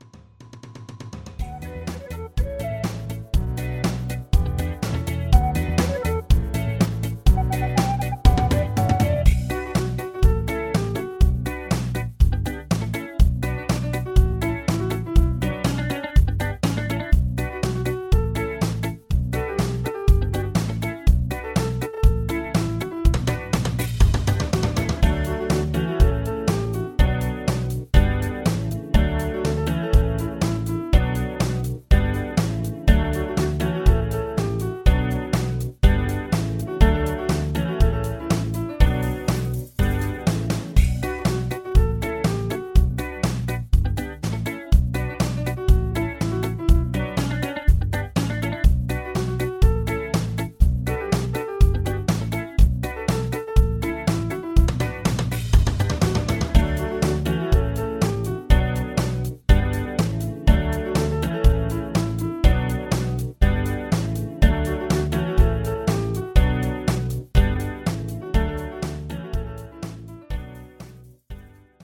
Instrumental Lower
Singing Calls